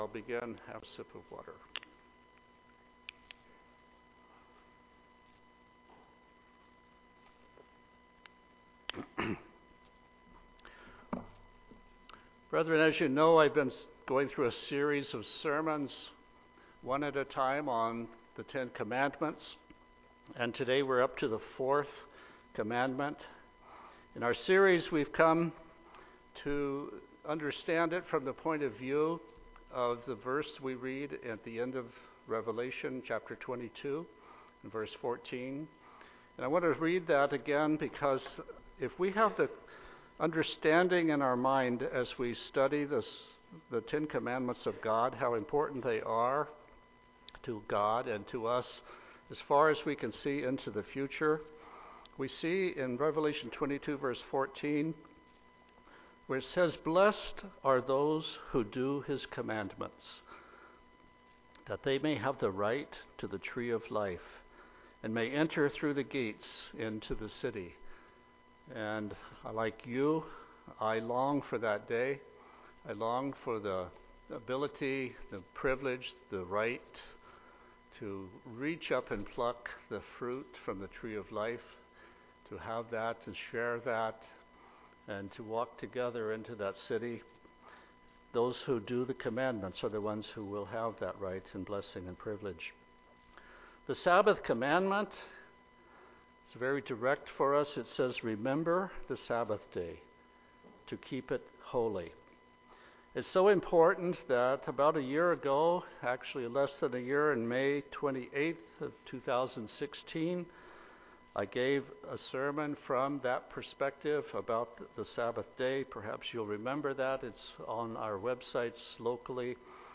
Given in Tacoma, WA